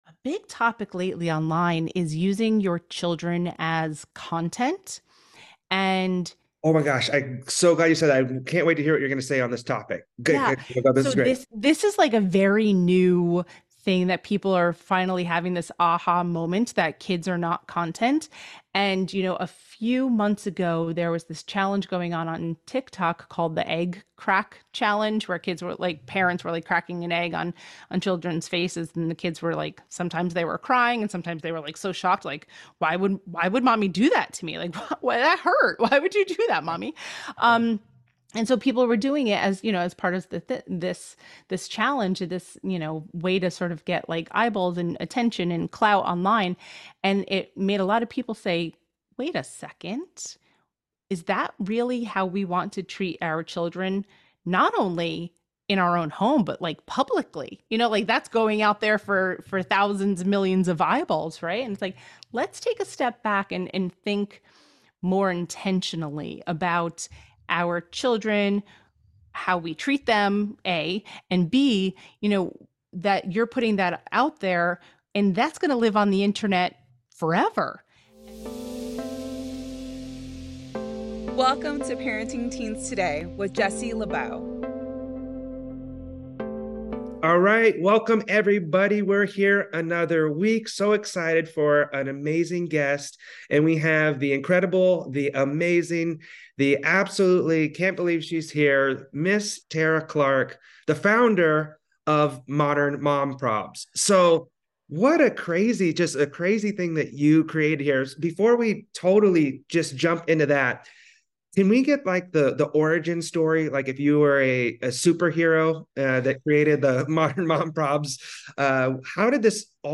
interviews special guest